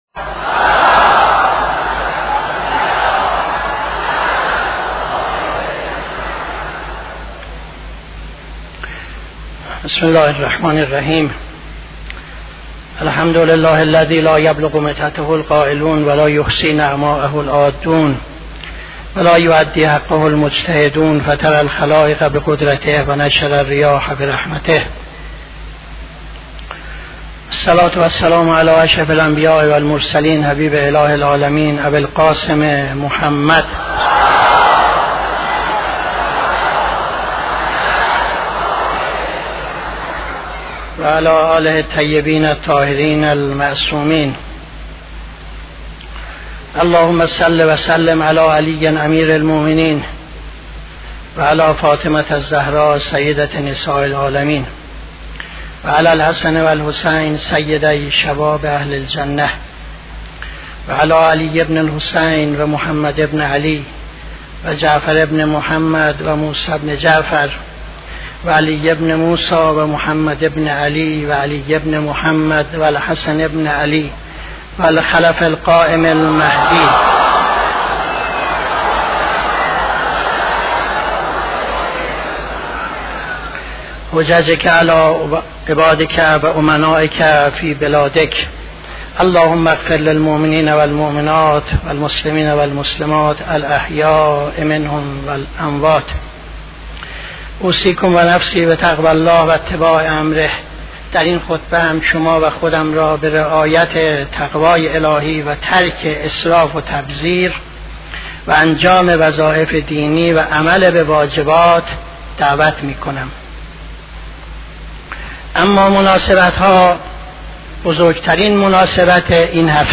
خطبه دوم نماز جمعه 14-01-77